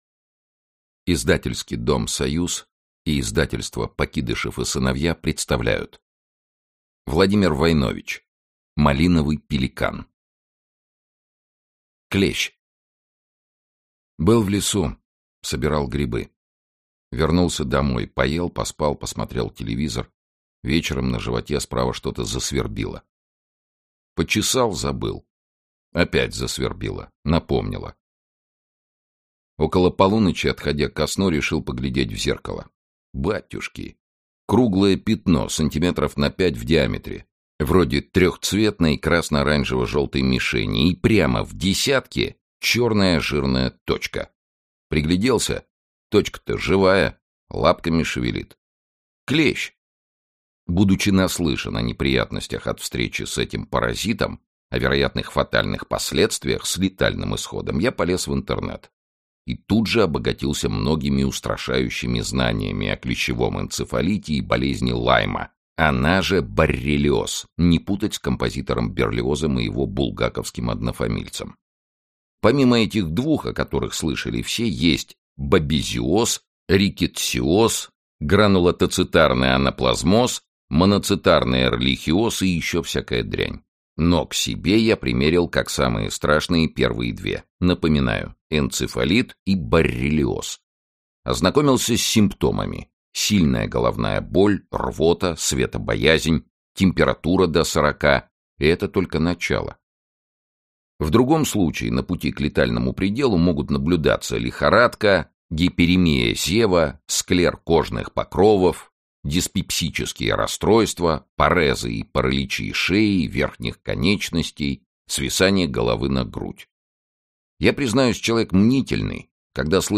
Аудиокнига Малиновый пеликан | Библиотека аудиокниг
Aудиокнига Малиновый пеликан Автор Владимир Войнович Читает аудиокнигу Сергей Чонишвили.